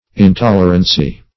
Search Result for " intolerancy" : The Collaborative International Dictionary of English v.0.48: Intolerancy \In*tol"er*an*cy\ ([i^]n*t[o^]l"[~e]r*an*s[y^]), n. Intolerance.